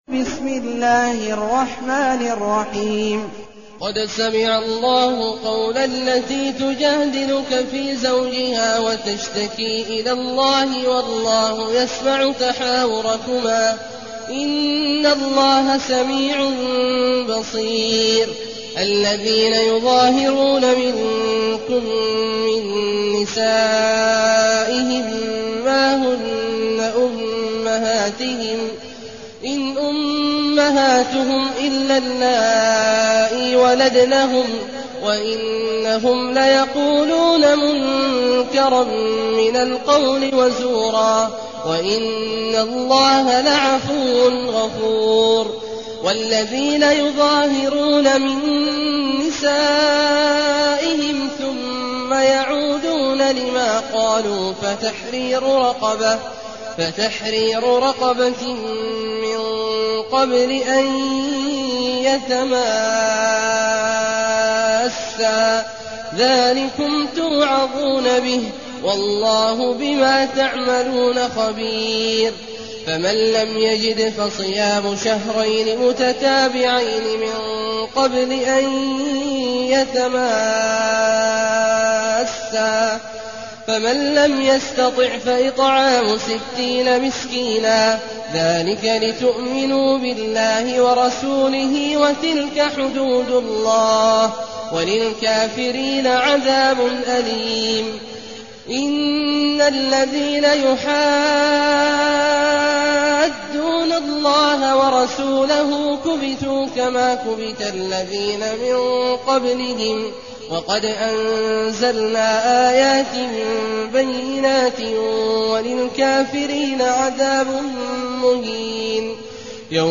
المكان: المسجد الحرام الشيخ: عبد الله عواد الجهني عبد الله عواد الجهني المجادلة